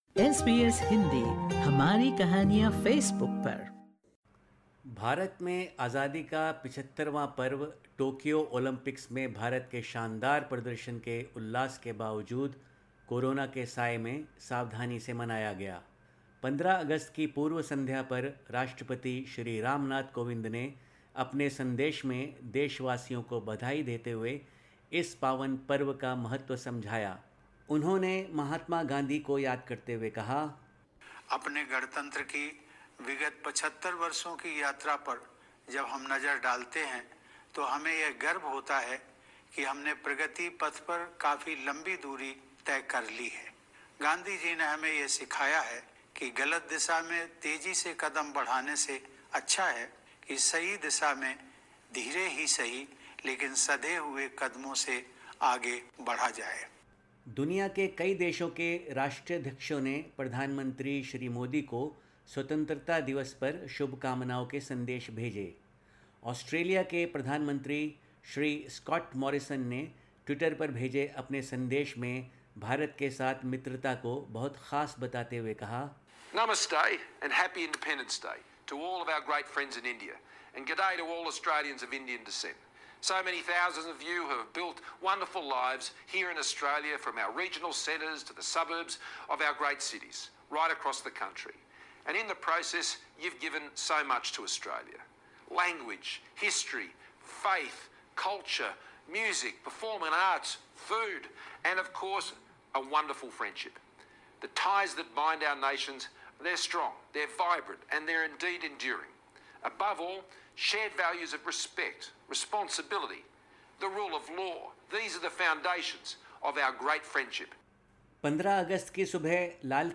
sbs_hindi_news_bulletin_august_16_0.mp3